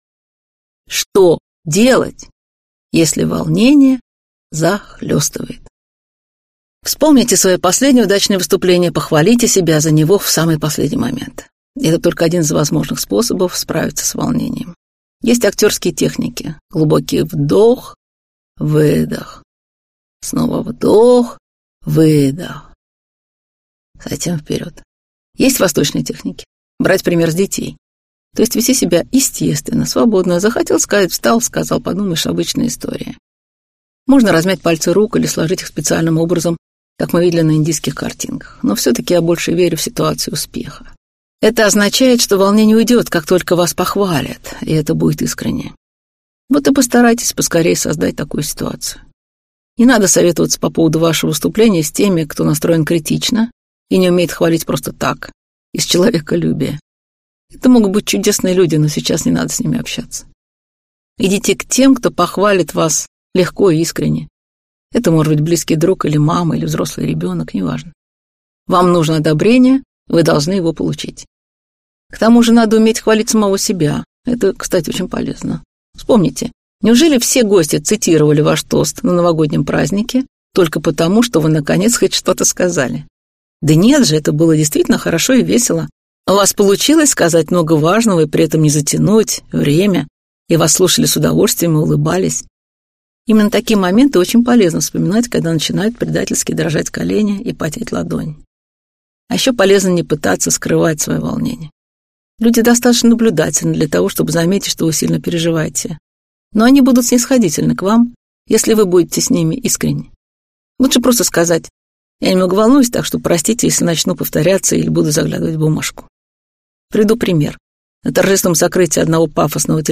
Аудиокнига Вам слово! Выступление без волнения | Библиотека аудиокниг